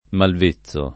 malv%ZZo] s. m. — anche mal vezzo [id.]: è mal vezzo di critici superficiali e ripetitori [